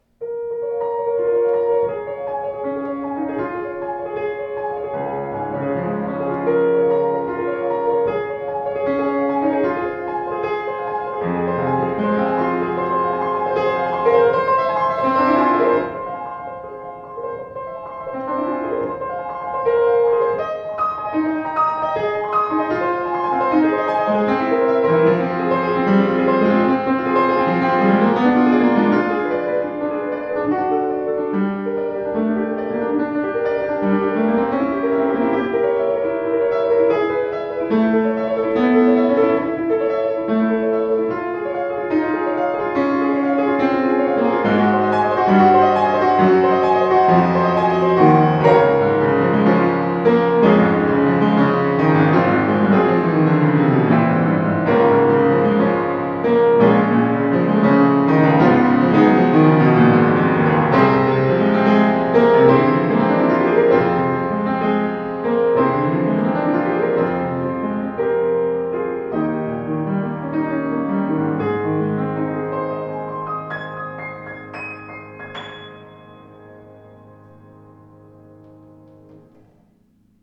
Voller, gestaltungsfähiger Klang, angenehm flüssige Spielart.